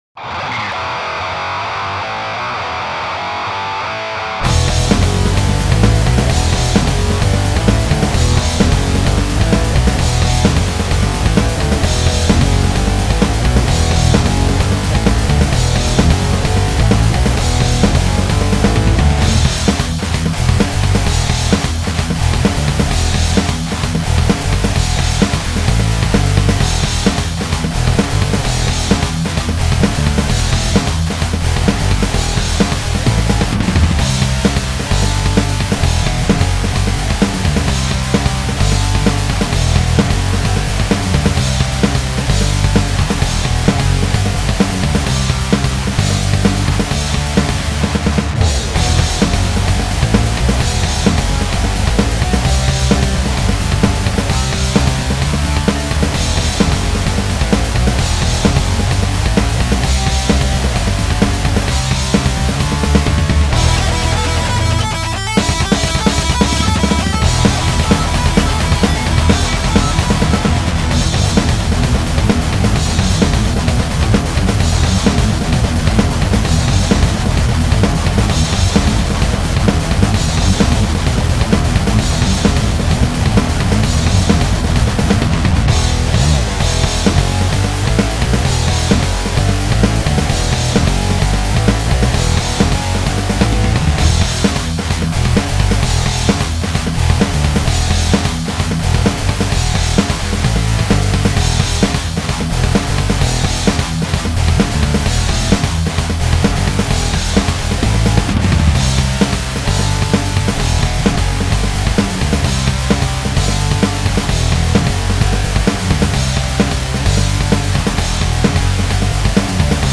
[07/03/03] �]�ƕ��ɃY���Y�������悤�ȏd�ቹ��ڎw���Ă݂��B ����w�b�h�t�H���ő剹�ʂɂ��Ė�����ł���B BPM130�Ȃ̂Ɏ��������ӂ��g���b�N�Ɏd�オ�����B �������M�^�[�\���Řr�O�̒Ⴓ���o����ȁBorz